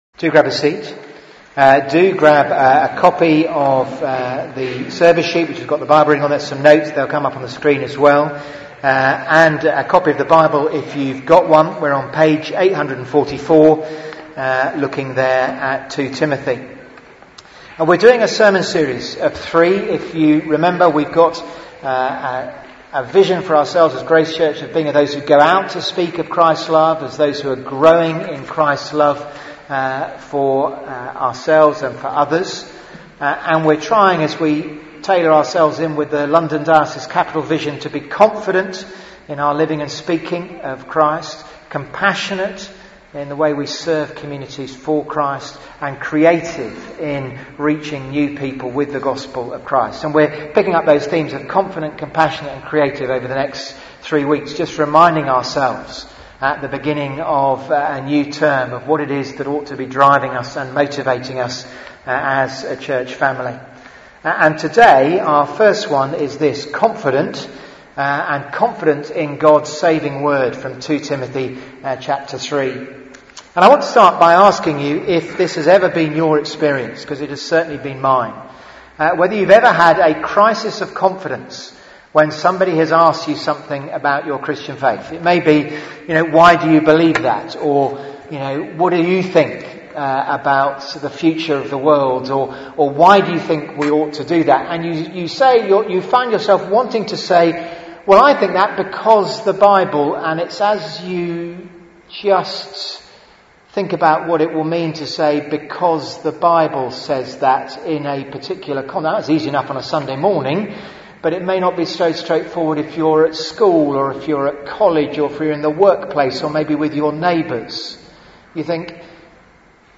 Theme: Confident...in God's saving word Sermon